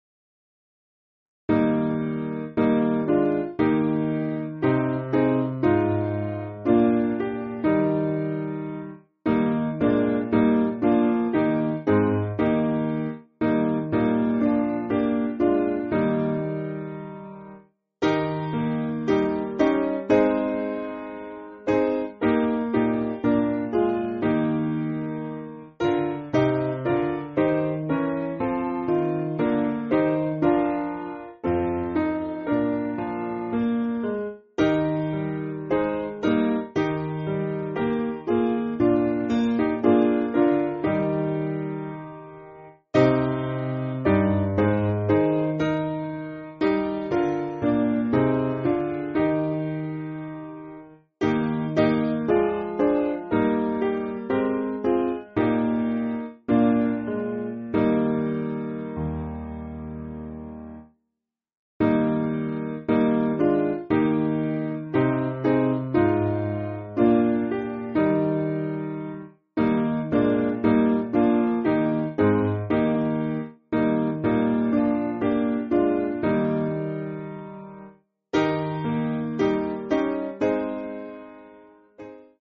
Simple Piano
(CM)   3/Eb